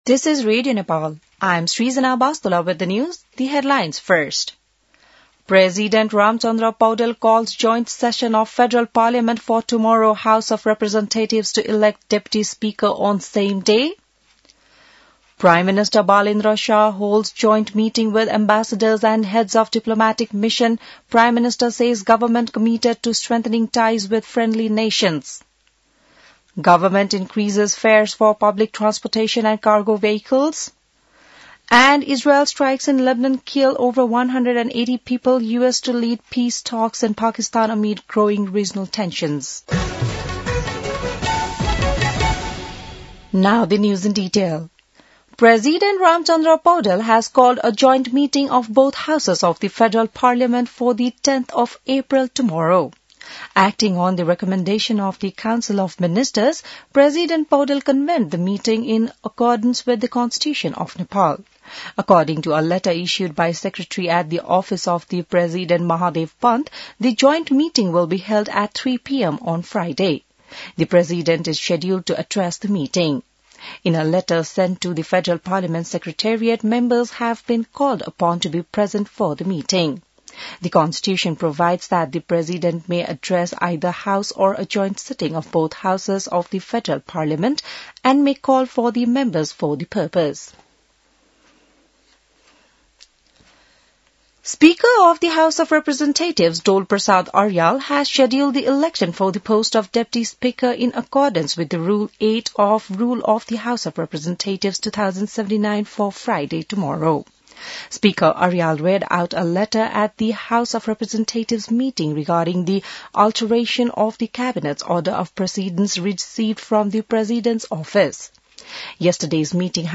बिहान ८ बजेको अङ्ग्रेजी समाचार : २६ चैत , २०८२